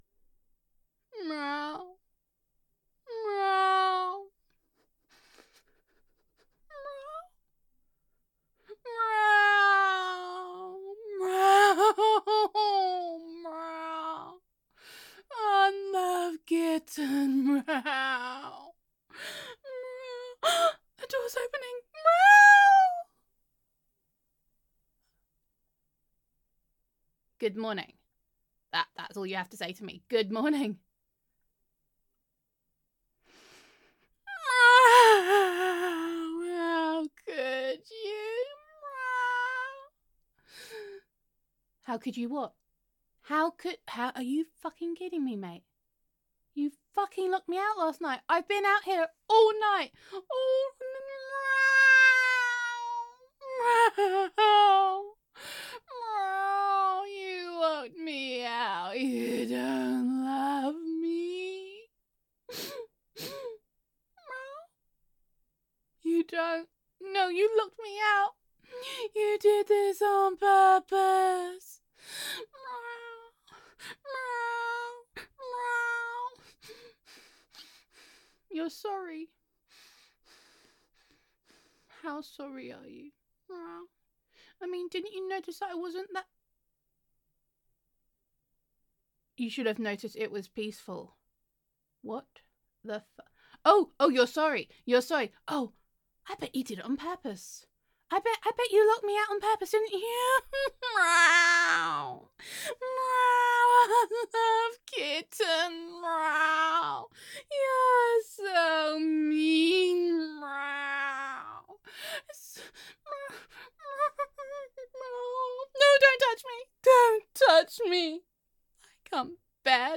[F4A] You Locked Me Out All Night [Unloved Kitten][You’re so Mean][It Was Claw-Ful][I Am Not Hiss-Terical][You Fur-Got About Me][High Maintenance Catgirl Roleplay][Drama Cat][Gender Neutral][Neko Roleplay]